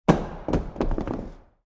Звуки гантелей
Звук упавшей гантели на мат